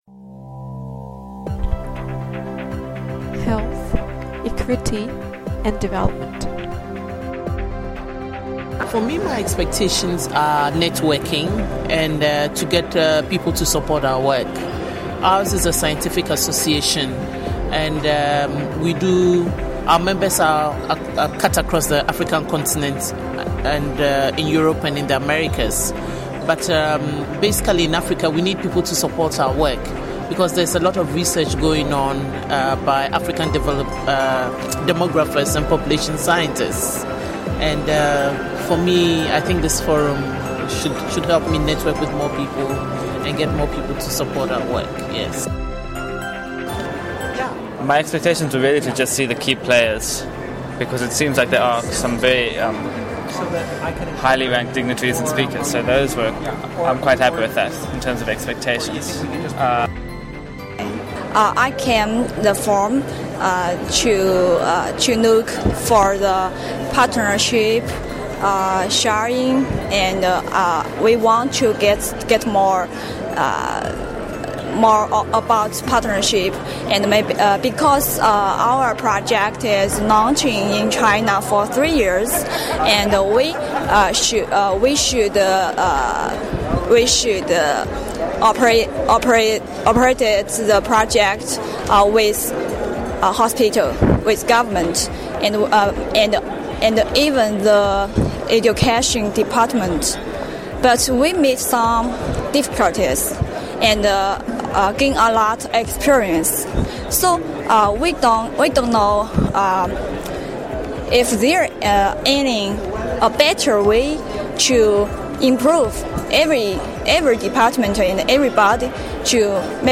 Voxpop at Forum2012